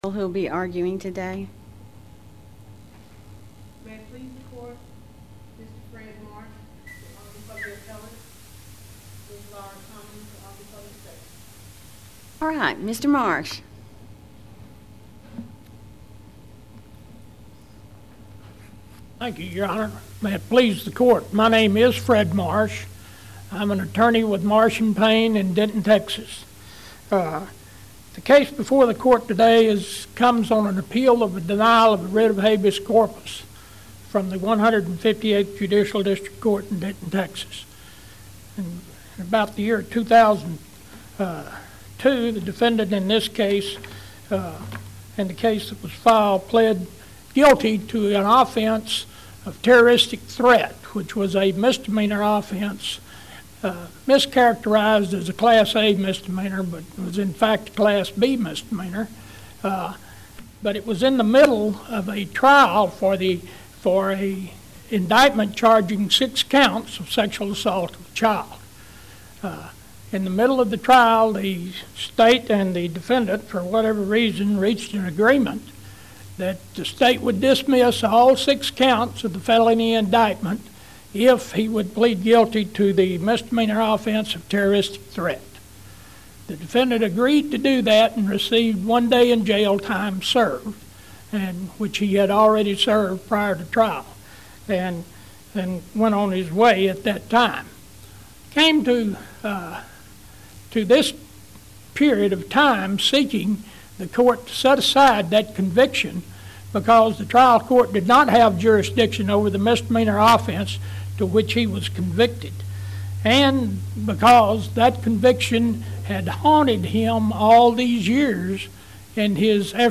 TJB | 2nd COA | Practice Before the Court | Oral Arguments | 2015